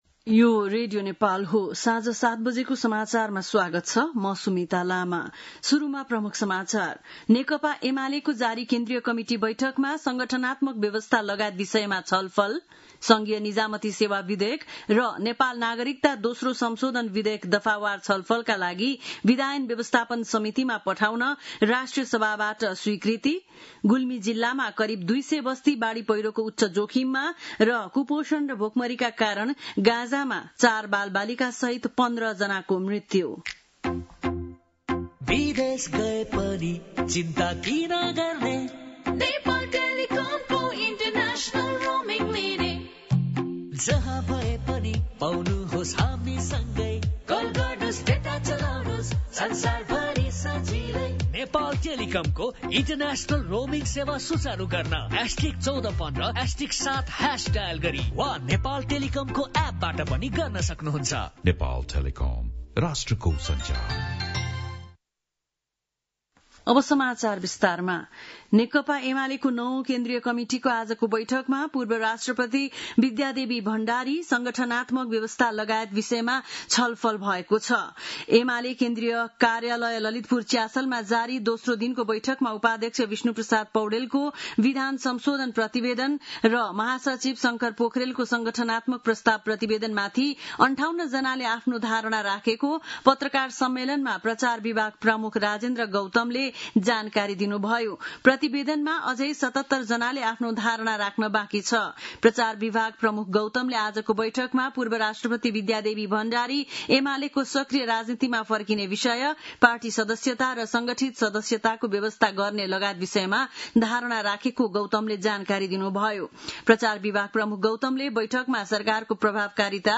बेलुकी ७ बजेको नेपाली समाचार : ६ साउन , २०८२